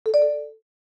Download Free Notification Sound Effects | Gfx Sounds
Notification-alert-9.mp3